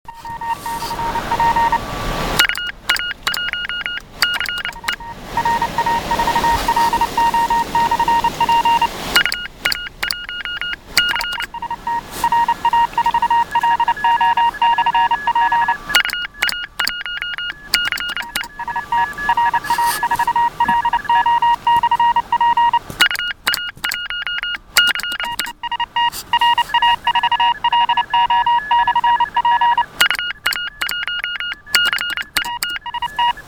Участвовал в "Полевом дне" честной QRP/p мощностью.
Ипару аудио, что бы проникнуться атмосферой теста, кстати вете был такой силы, что на аудио слышно.